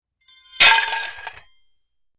Metal_70.wav